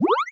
Heal2.wav